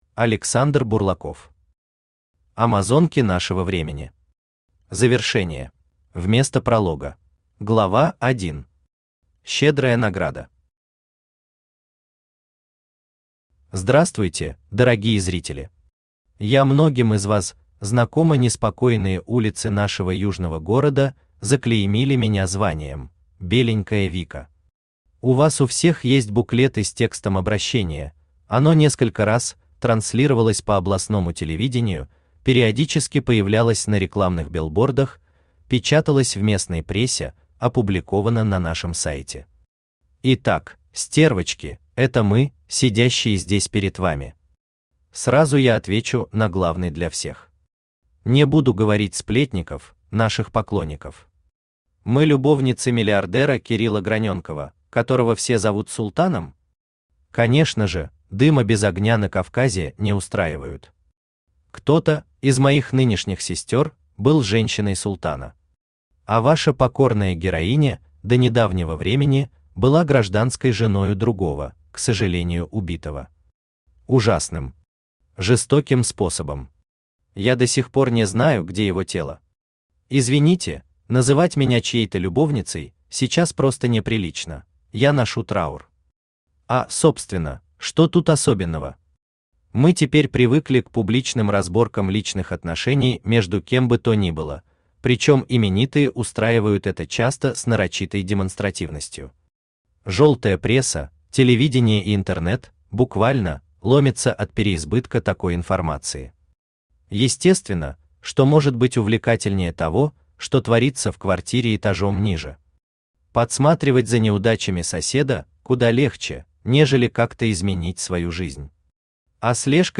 Аудиокнига Амазонки нашего времени. Завершение | Библиотека аудиокниг
Завершение Автор Александр Бурлаков Читает аудиокнигу Авточтец ЛитРес.